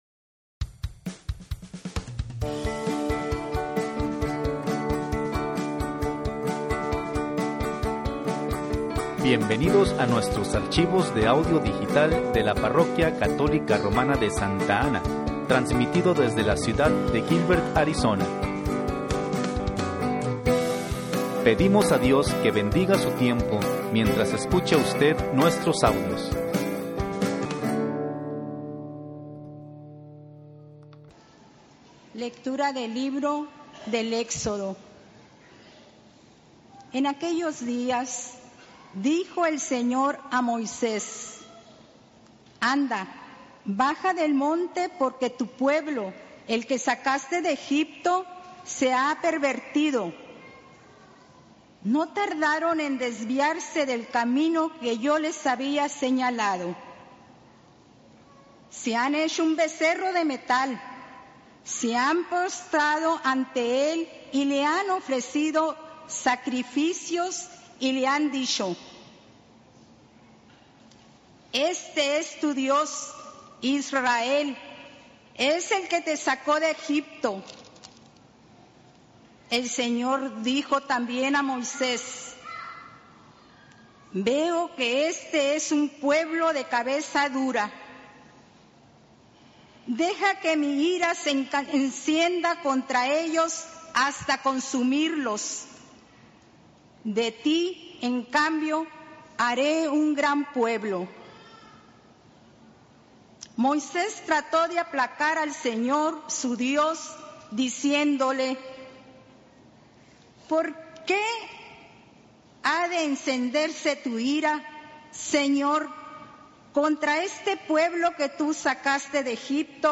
Decimo Cuarto Domingo del Tiempo Ordinario (Lecturas) | St. Anne